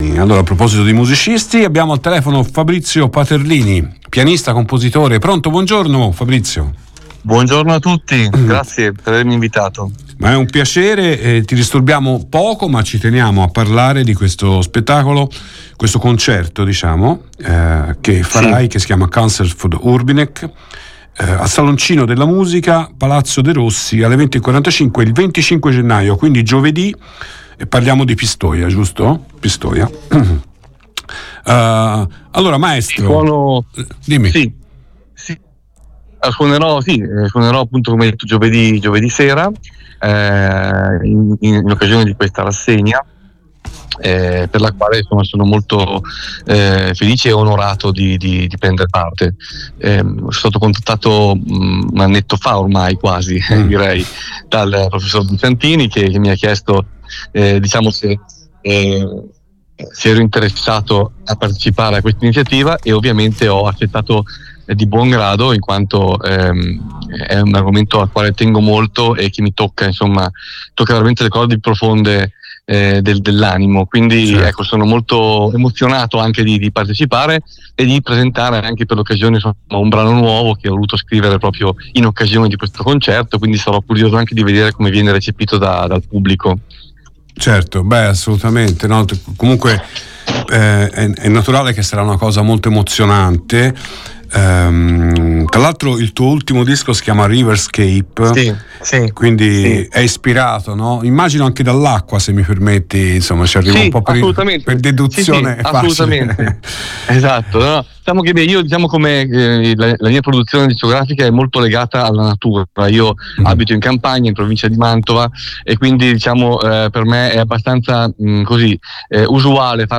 Pianista e compositore di fama internazionale
per piano solo